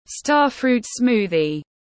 Sinh tố khế tiếng anh gọi là starfruit smoothie, phiên âm tiếng anh đọc là /’stɑ:r.fru:t ˈsmuː.ði/